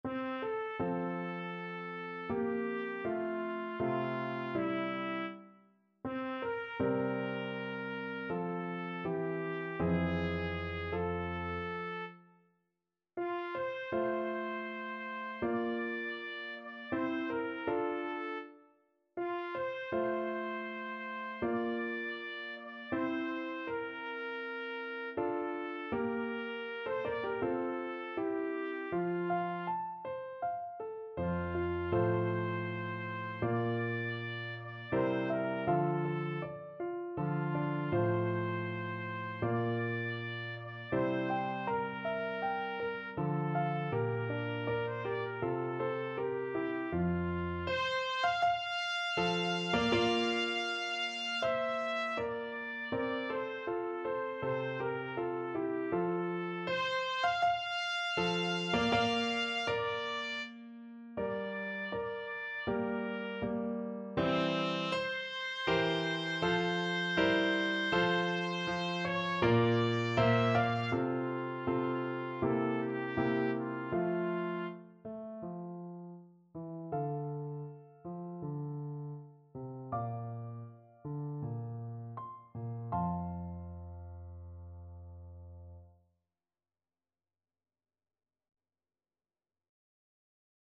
Classical Mozart, Wolfgang Amadeus Contessa, perdono! Trumpet version
Trumpet
F major (Sounding Pitch) G major (Trumpet in Bb) (View more F major Music for Trumpet )
4/4 (View more 4/4 Music)
Andante
Classical (View more Classical Trumpet Music)
mozart_figaro_contessa_perdono_TPT.mp3